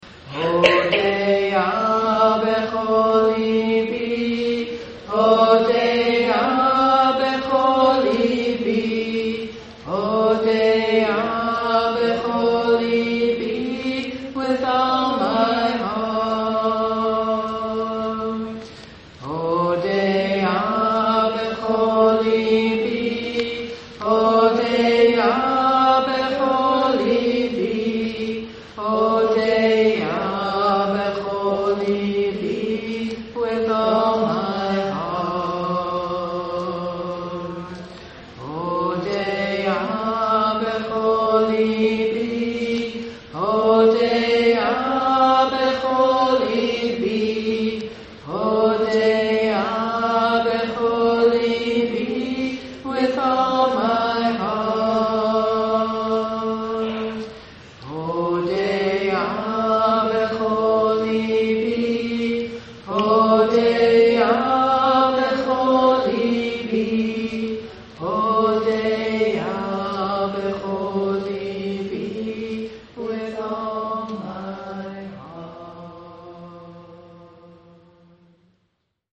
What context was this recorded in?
For more than 15 years we met monthly in the Reutlinger Community Synagogue.